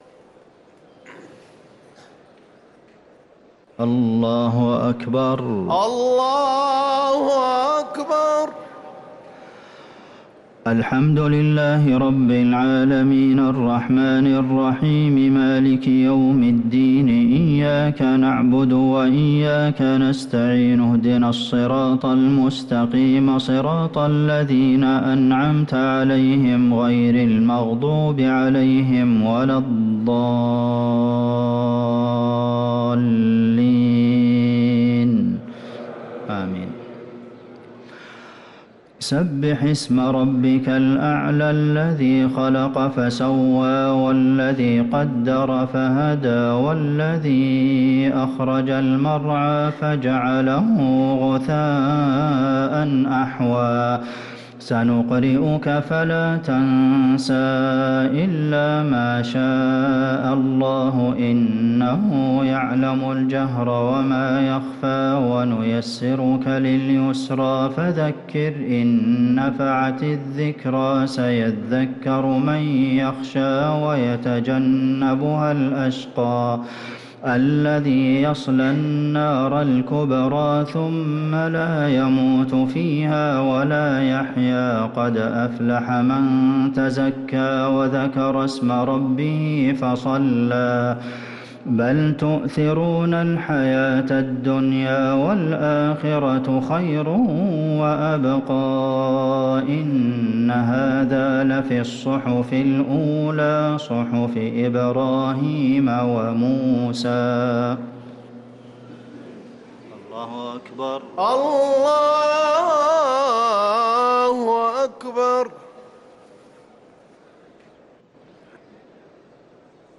الشفع و الوتر ليلة 29 رمضان 1444هـ | Witr 29 st night Ramadan 1444H > تراويح الحرم النبوي عام 1444 🕌 > التراويح - تلاوات الحرمين